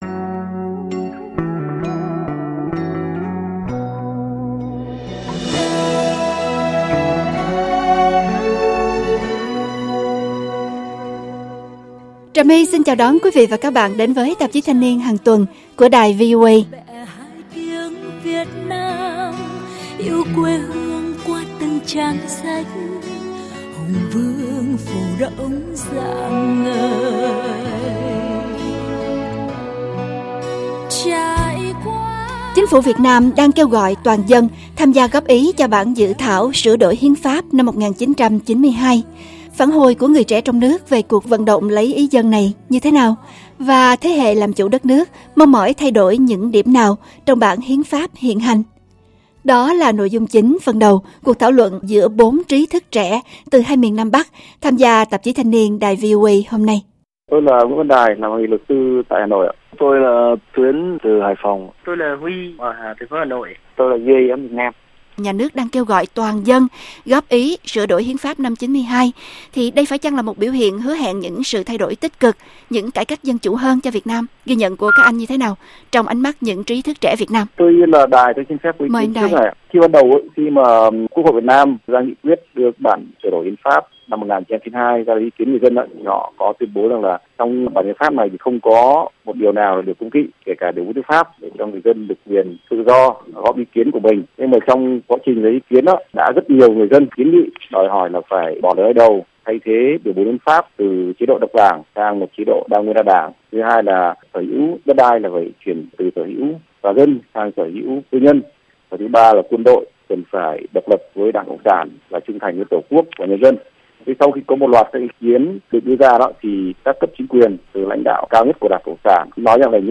Chính phủ Việt Nam đang kêu gọi toàn dân tham gia góp ý cho bản dự thảo sửa đổi Hiến Pháp 1992. Phản hồi của người trẻ trong nước về cuộc vận động lấy ý dân này như thế nào và thế hệ làm chủ đất nước mong mỏi thay đổi những điểm nào trong bản Hiến Pháp hiện hành? Đó là nội dung chính phần đầu cuộc thảo luận giữa 4 trí thức trẻ từ hai miền Nam-Bắc tham gia trên Tạp chí Thanh Niên đài VOA hôm nay.